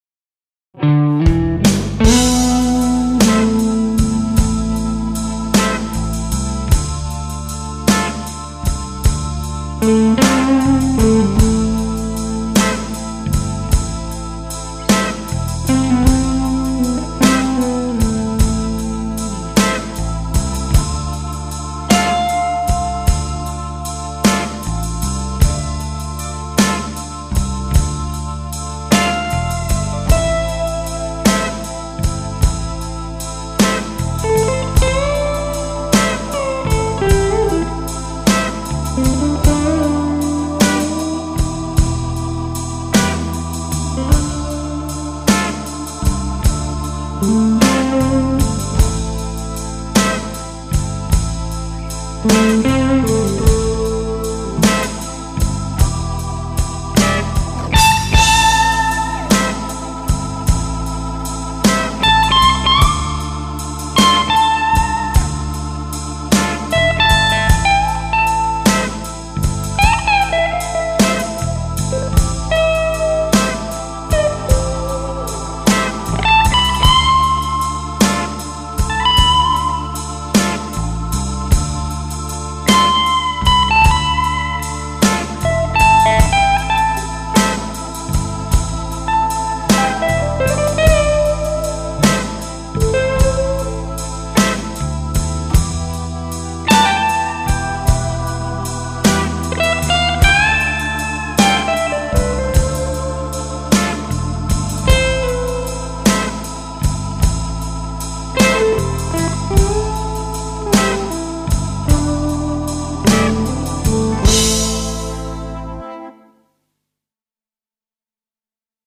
Gestern abend habe ich dann noch einen weiteren Take mit der Explorer über die lange Version eingespielt, vielleicht poste ich den mal die Tage, jetzt möchte ich Euch aber meine allererste Version zu diesem Backing vorstellen, die habe ich schon Anfang Juni mit meiner MelodyMaker und dem Womanizer eingespielt: